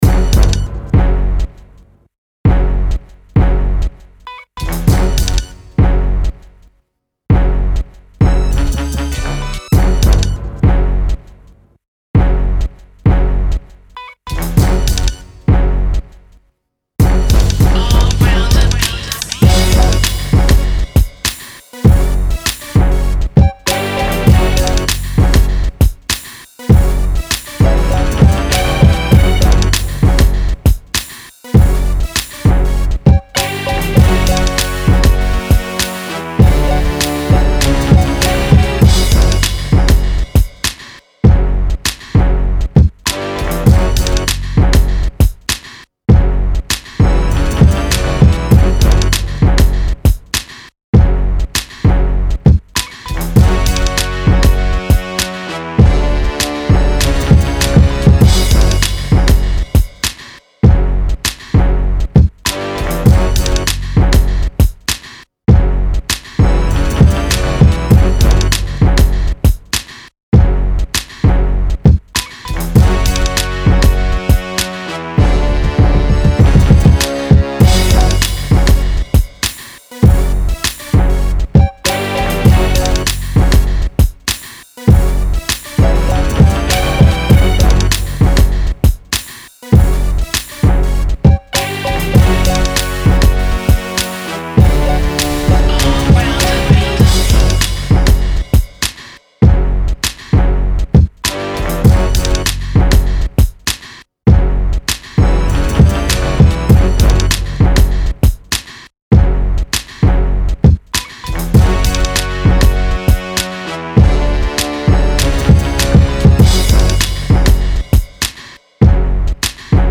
ловите лучшие 10 минусов, пряных, качающих, сочных.
минус 4